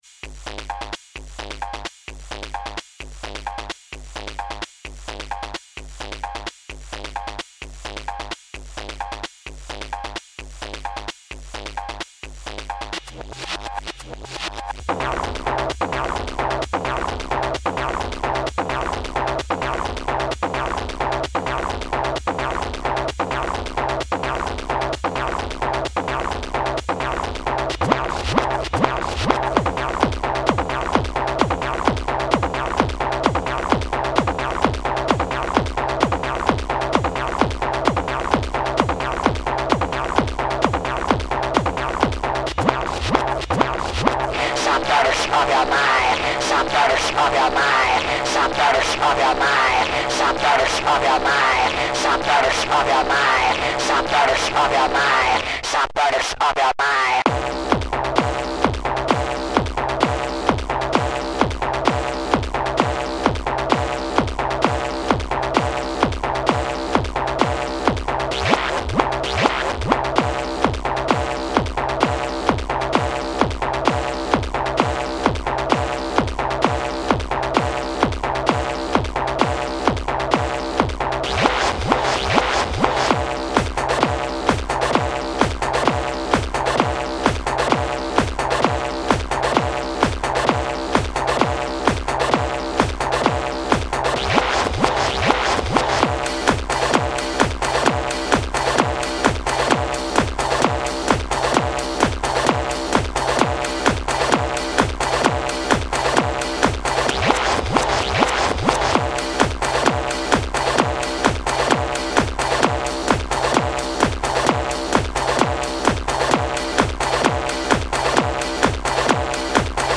Žánr: Electro/Dance